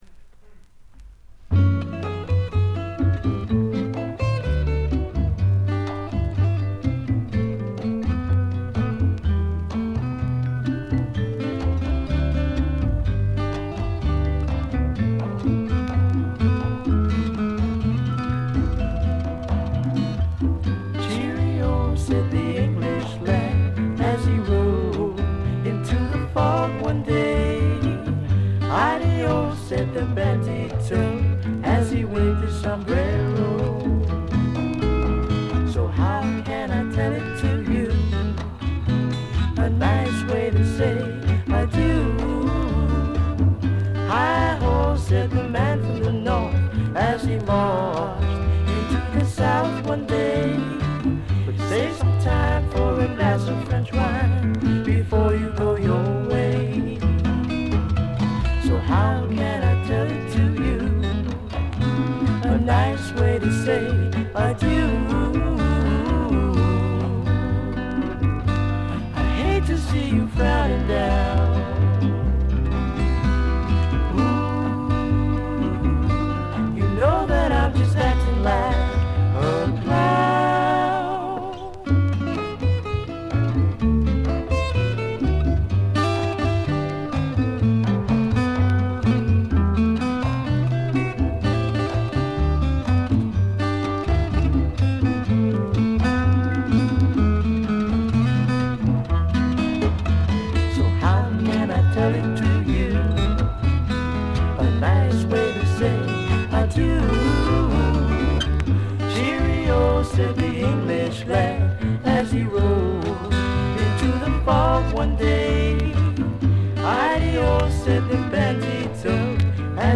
軽微なバックグラウンドノイズ。
このデビュー作はフォーキーなグッタイム・ミュージックの傑作です。
試聴曲は現品からの取り込み音源です。